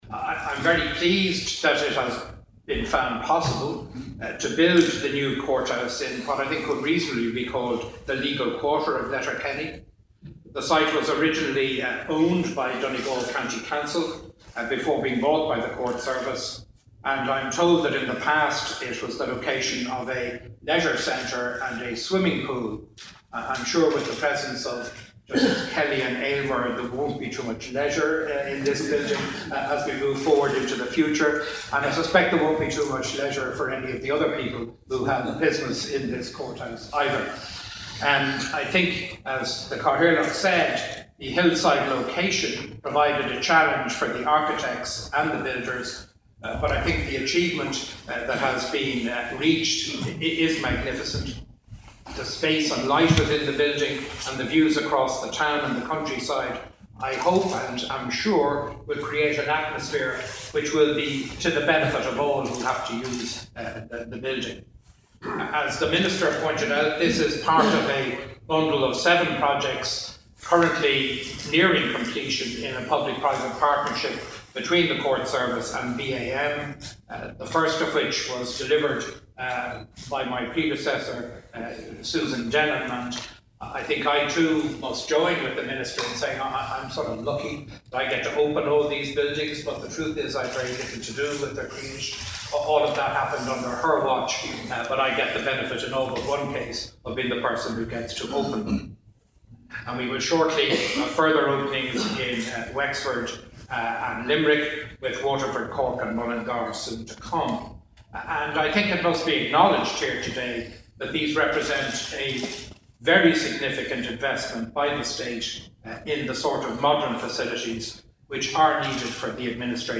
The Chief Justice of Ireland, Mr Justice Frank Clarke, today said the building is an impressive and important part of the progressive modernisation of the courts service since its establishment: